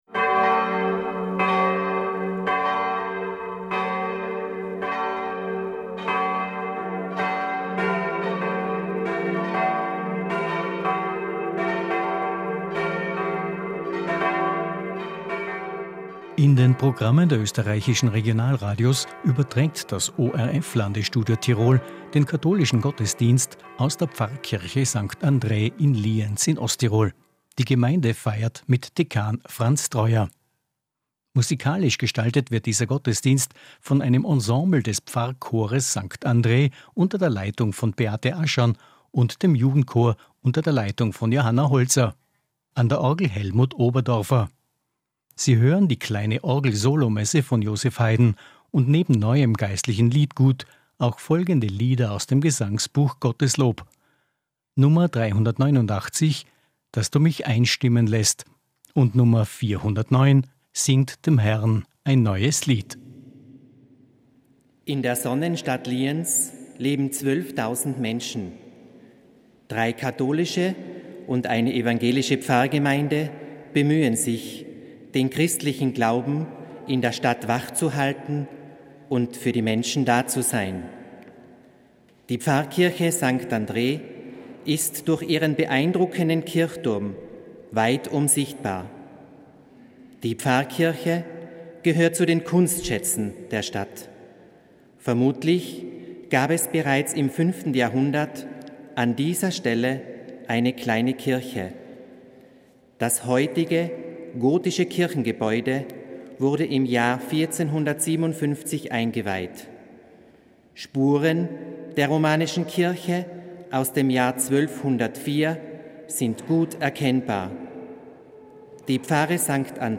Jeder einzelne von uns ist aufgerufen, als Christ auf seine Verantwortungen zu antworten. Diese Botschaft bildete den roten Faden durch die festliche Messfeier bei der acht LektorInnen passende Texte beitrugen.
Radiogottesdienst am Sonntag, 6.9.2020 um 10 Uhr live aus unserer Pfarrkirche!